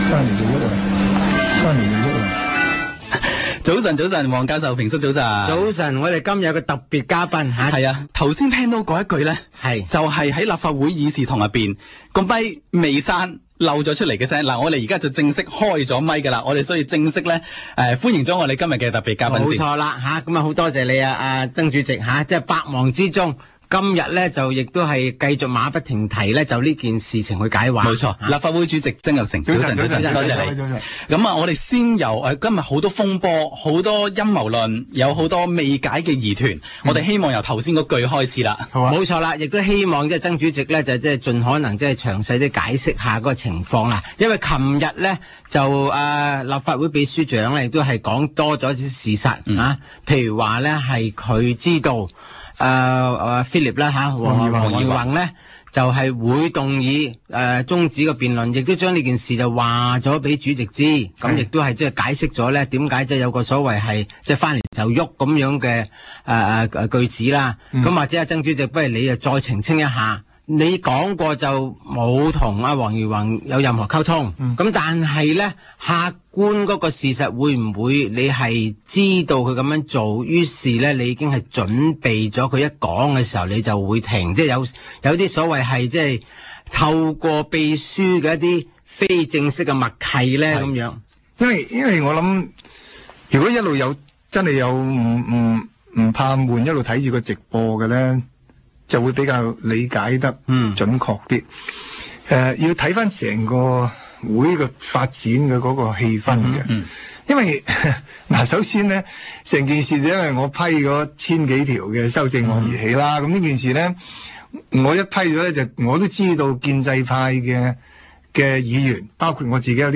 商業電台《不平平則鳴》訪問